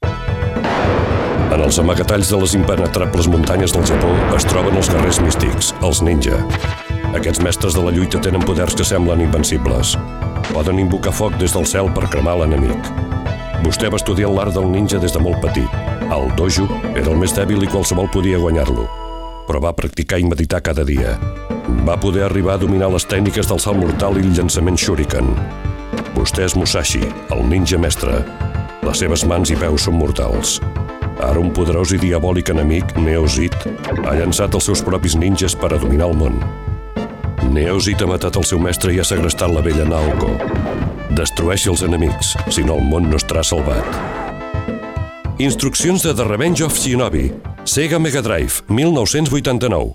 Secció anomenada "L'argument" on es llegeix l'argument escrit en el manual del videojoc "The revenge of Shinobi" (1989)
Divulgació
FM